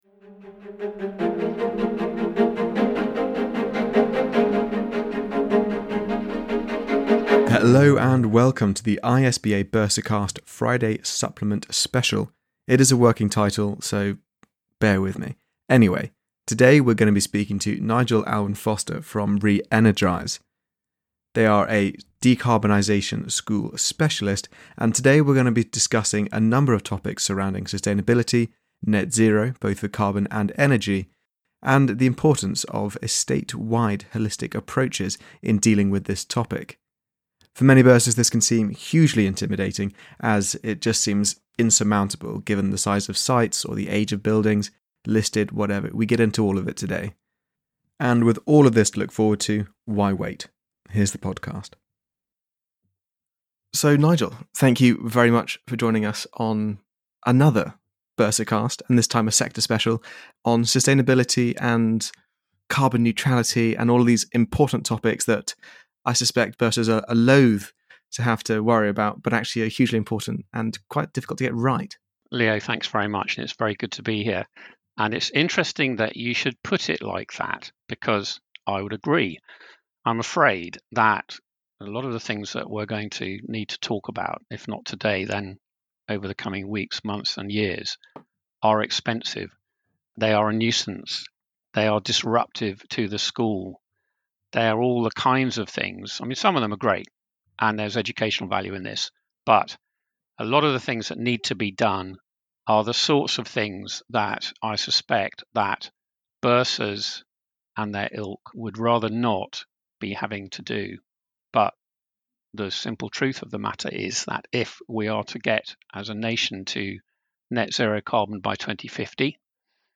discussion about school estate decarbonisation. The 35-minute podcast aims to dispel common myths and misconceptions, help bursars understand what to prioritise when looking at net-zero as a whole, and highlight the biggest carbon emitters found on the typical school estate.